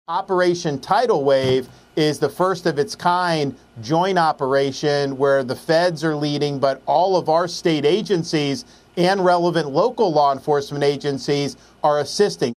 THE WHITE HOUSE IS TOUTING BORDER SECURITY SUCCESS AS PRESIDENT DONALD TRUMP REACHES 100 DAYS IF OFFICE… AND FLORIDA IS TAKING A SUPPORTING ROLE IN THAT ENDEAVOR. GOVERNOR RON DESANTIS EXPLAINS A RECENT STATEWIDE OPERATION WHICH RESULTED IN ALMOST 8-HUNDRED ARRESTS…